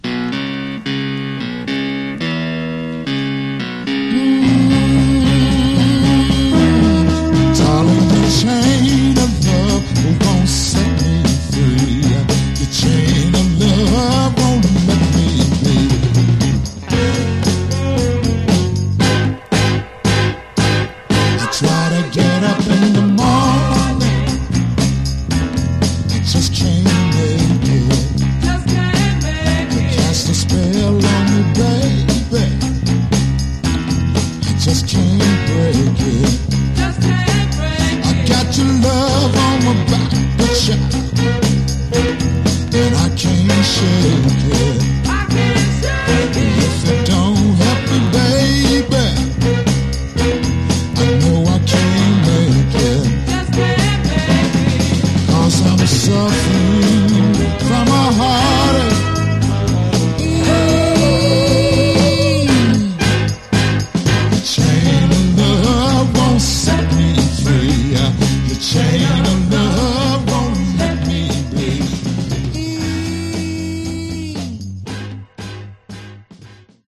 Genre: Other Northern Soul